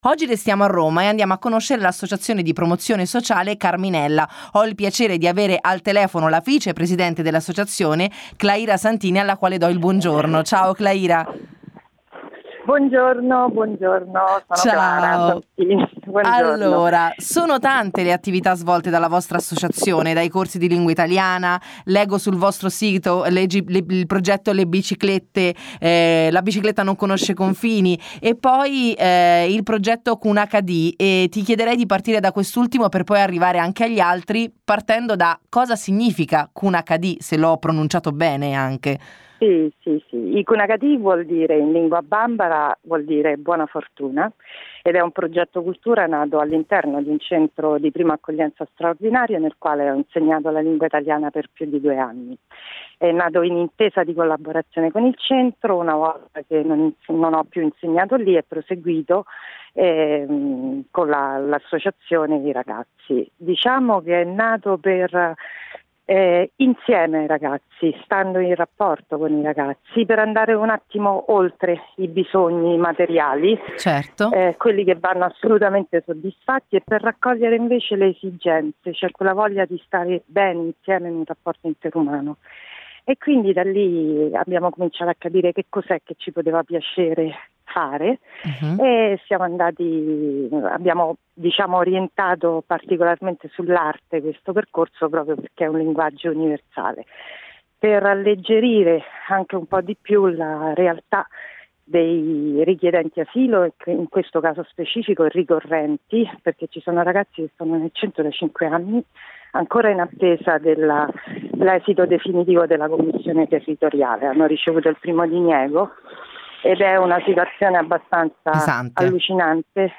Intervista_RadioInblu.mp3